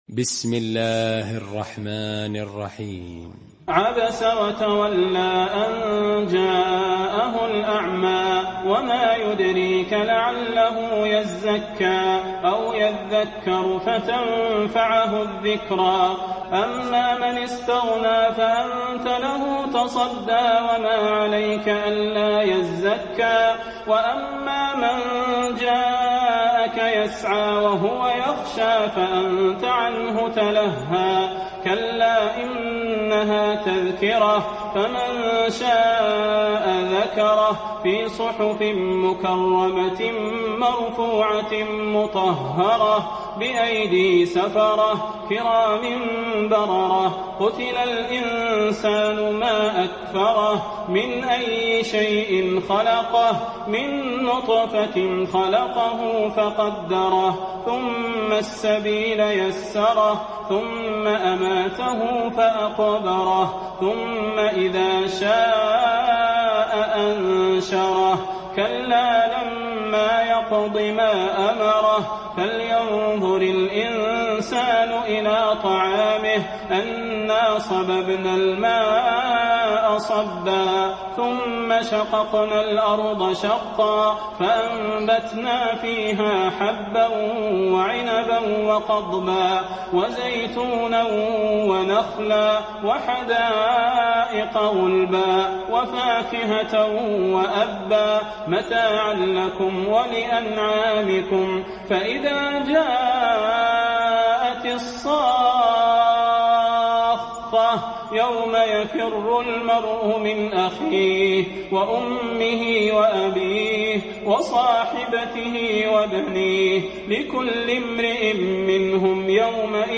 المكان: المسجد النبوي عبس